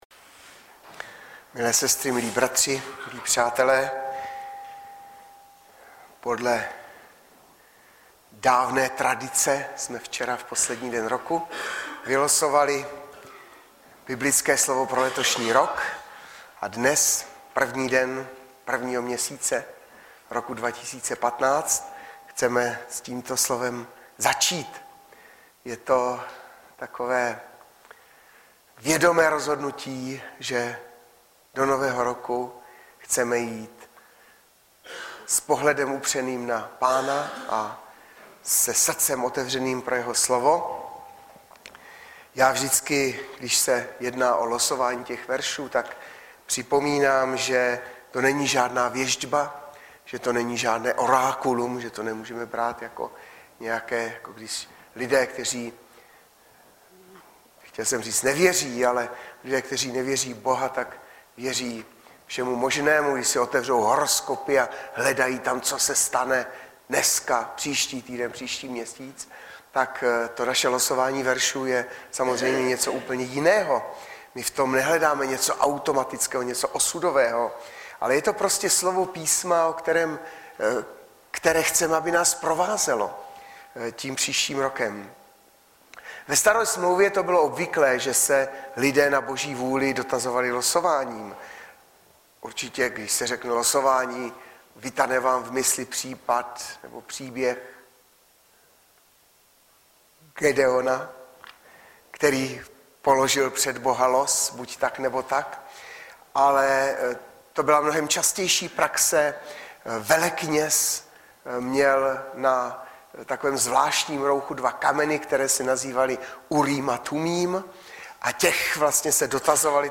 Baptisté v Litoměřicích
Kázání